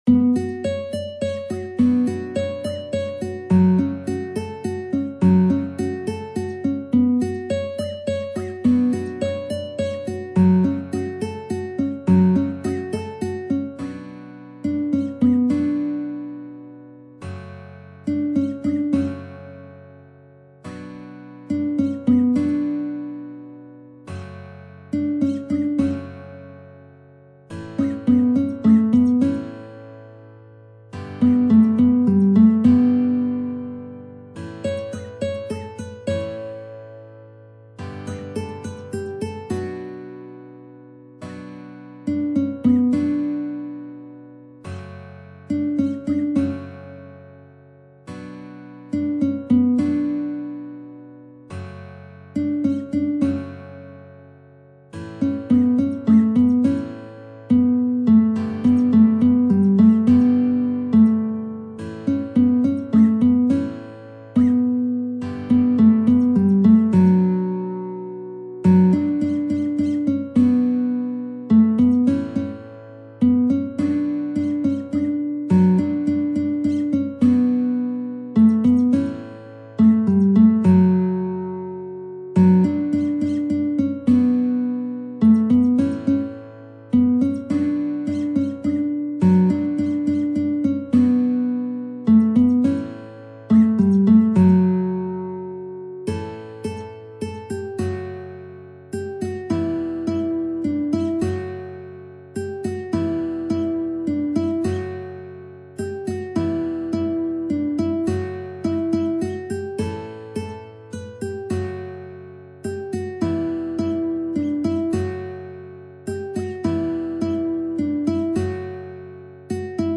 نت ملودی به همراه تبلچر و اکورد